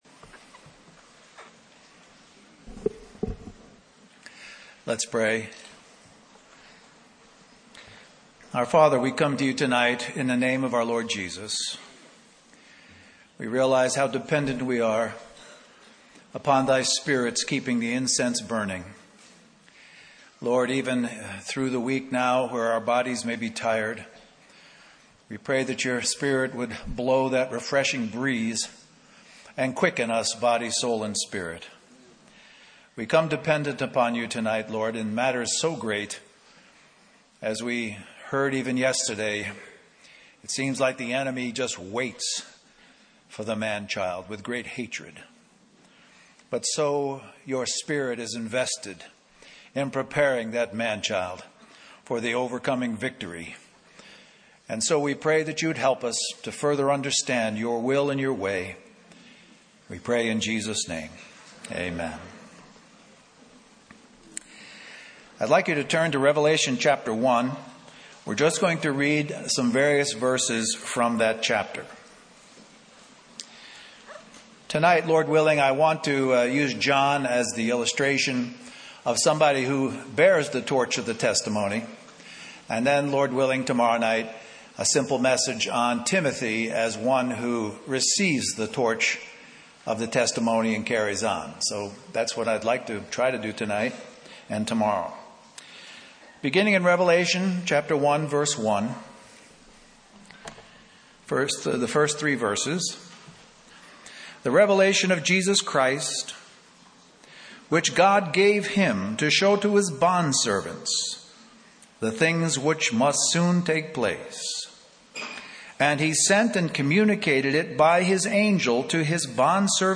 Christian Family Conference We apologize for the poor quality audio